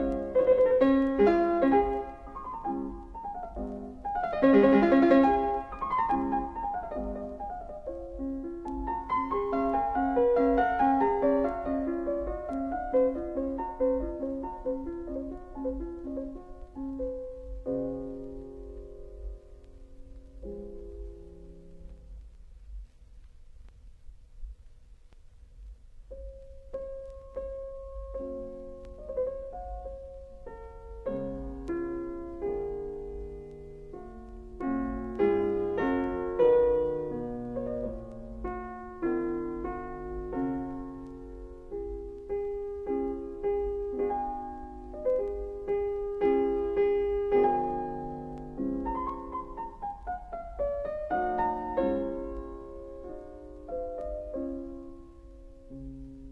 Mozart Sonata C Major K. 330 (Mitsuko Uchida, piano):
He ends with a C, and starts the second movement with same C.